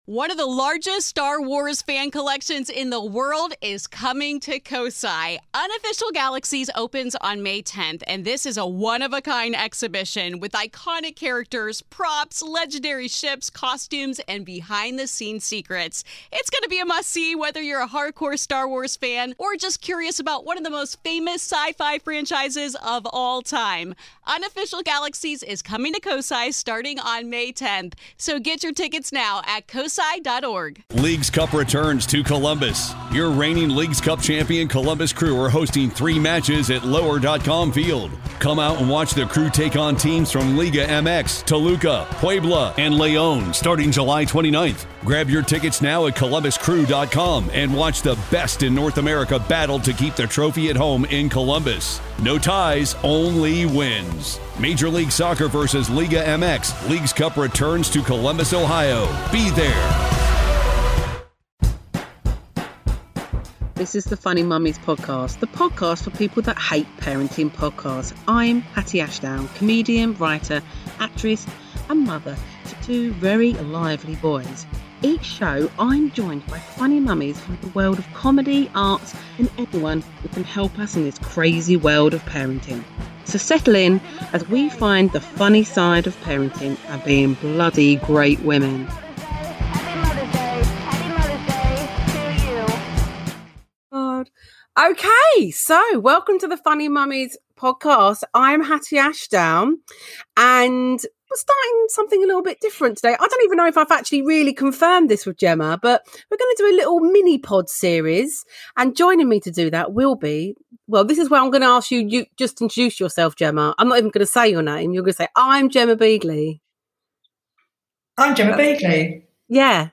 In this quick funny bum bag size show they discuss the Euro football games, do we give a toss ?